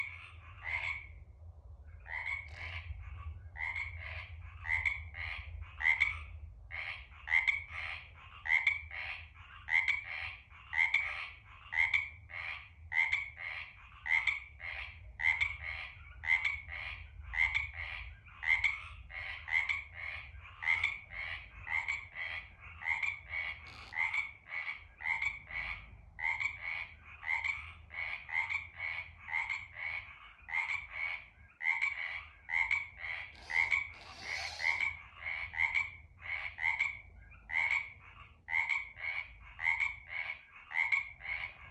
the sound of all that croaking was deafening.
Frog_Hollow_Farm_frogs_audio.m4a